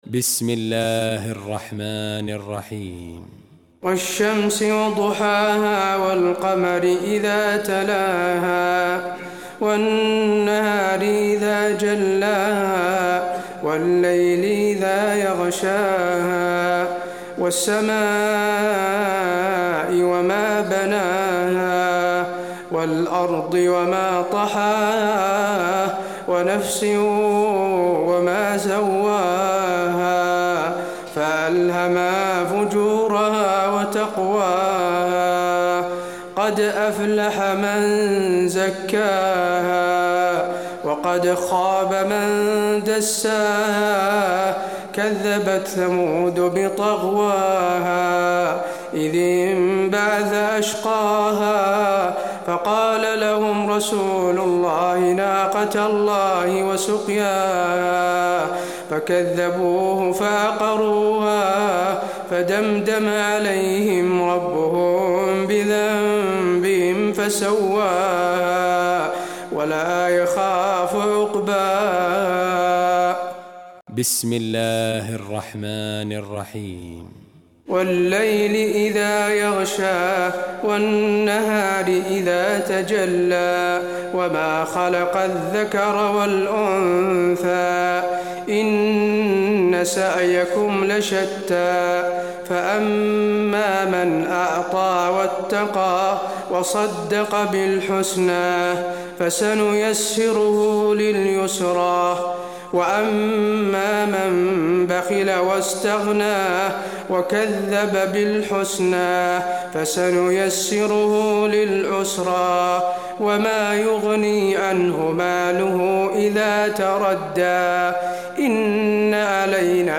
تراويح ليلة 29 رمضان 1426هـ من سورة الشمس الى الناس Taraweeh 29 st night Ramadan 1426H from Surah Ash-Shams to An-Naas > تراويح الحرم النبوي عام 1426 🕌 > التراويح - تلاوات الحرمين